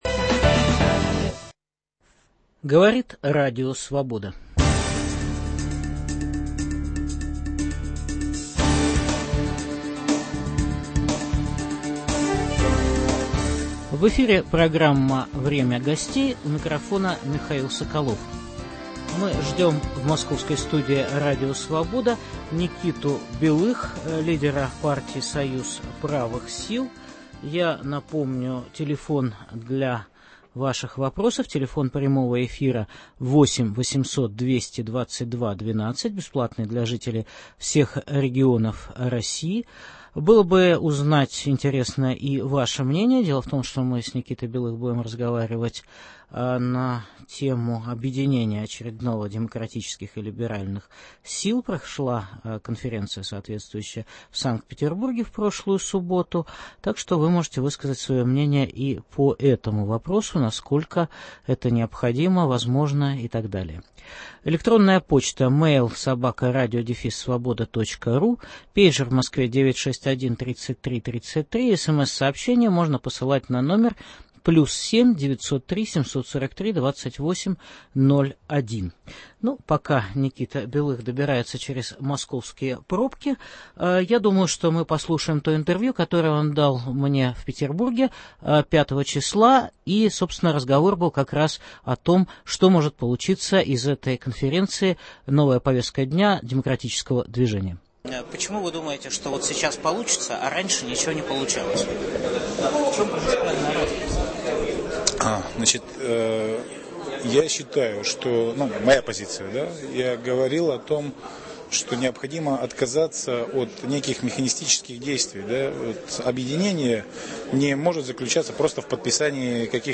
В программе выступит лидер партии Союз правых сил Никита Белых.